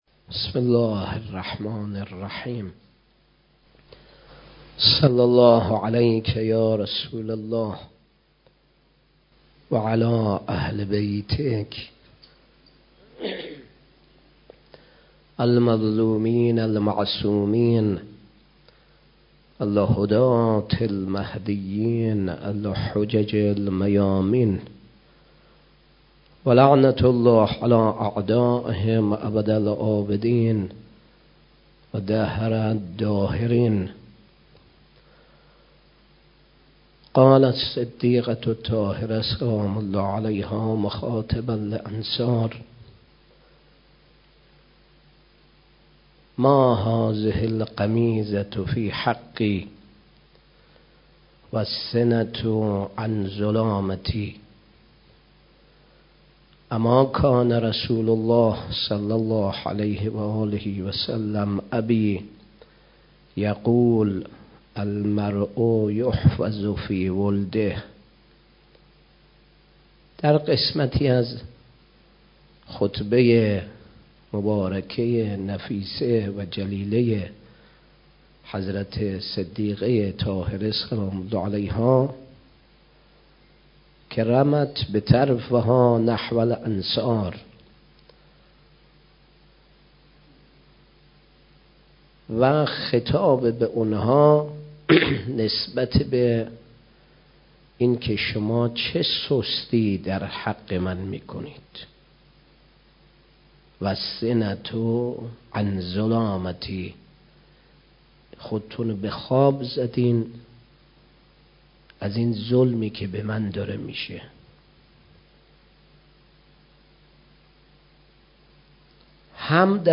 18 بهمن 97 - دفتر وحید خراسانی - سخنرانی